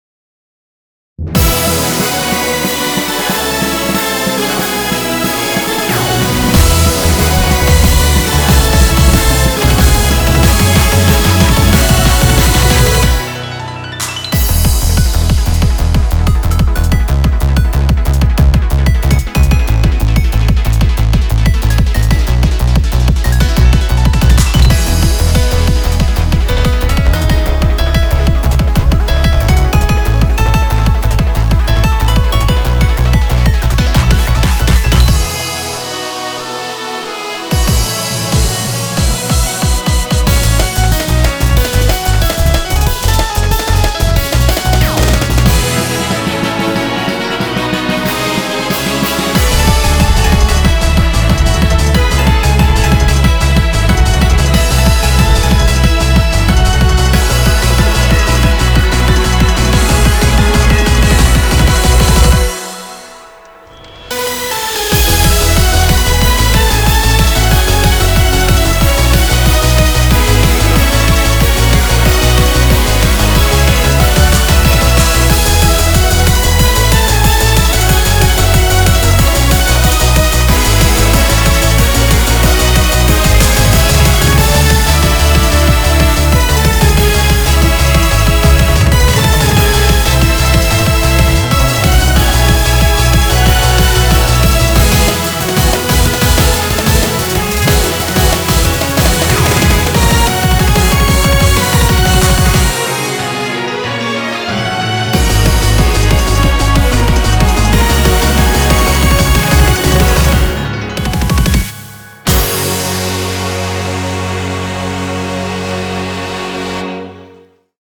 BPM185
Audio QualityPerfect (High Quality)